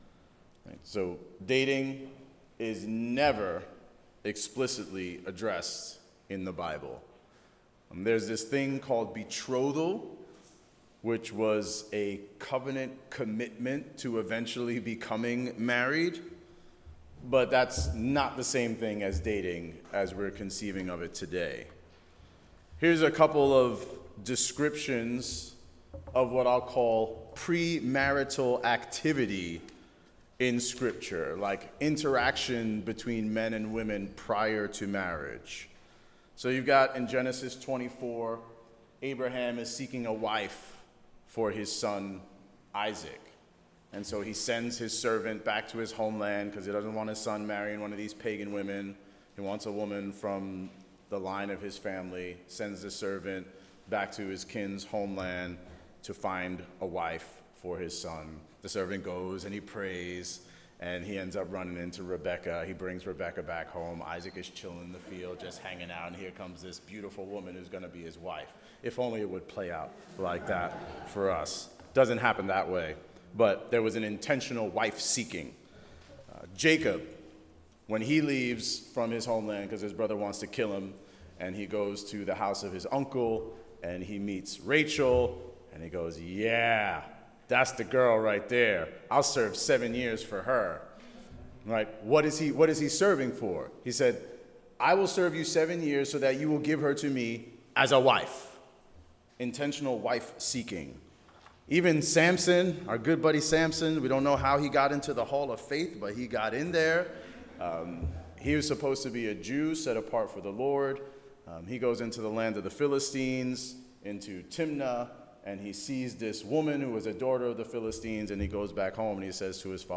Seminars Service Type: Special event